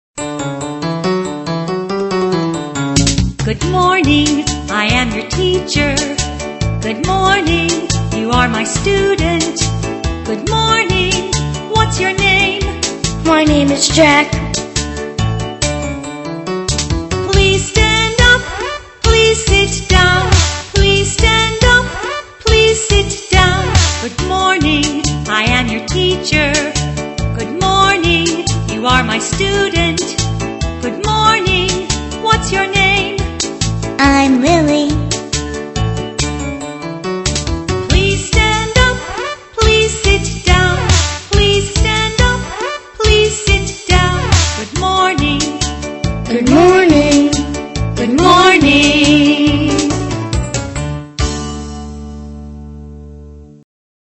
在线英语听力室英语儿歌274首 第51期:Good morning的听力文件下载,收录了274首发音地道纯正，音乐节奏活泼动人的英文儿歌，从小培养对英语的爱好，为以后萌娃学习更多的英语知识，打下坚实的基础。